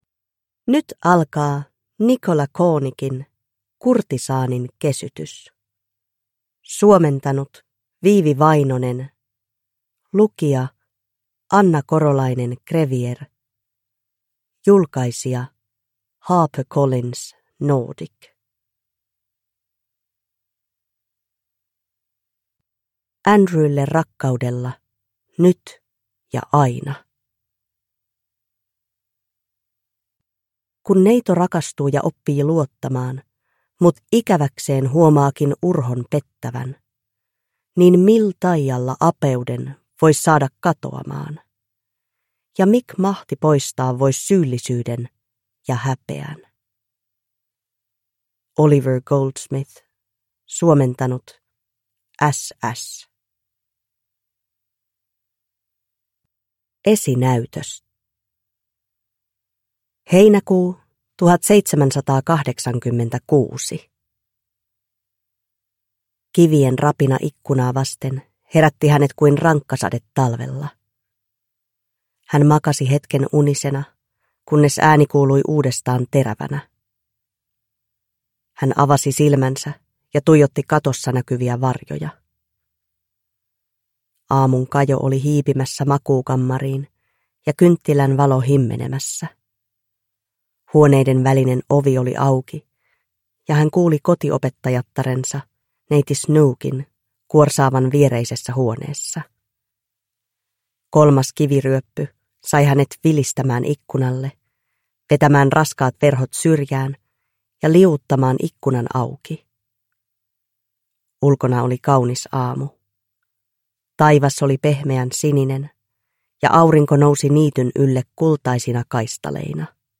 Kurtisaanin kesytys (ljudbok) av Nicola Cornick